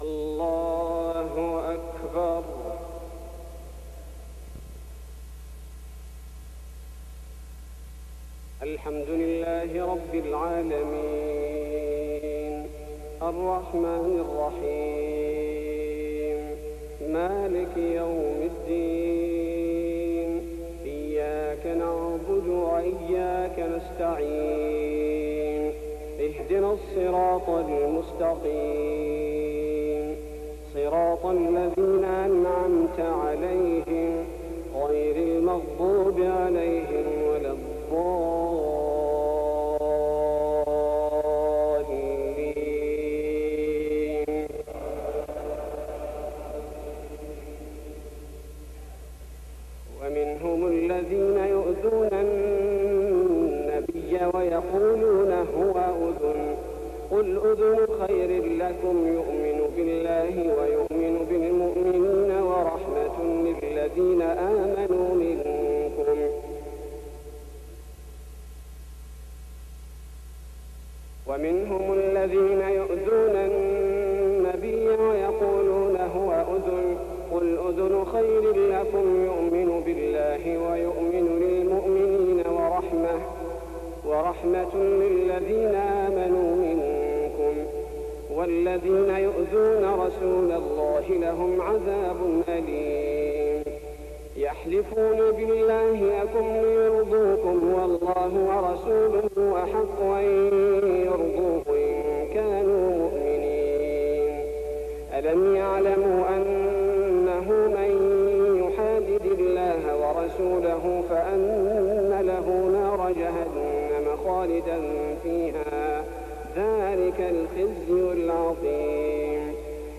تهجد ليلة 28 رمضان 1419هـ من سورة التوبة (61-96) Tahajjud 28th night Ramadan 1419H from Surah At-Tawba > تراويح الحرم النبوي عام 1419 🕌 > التراويح - تلاوات الحرمين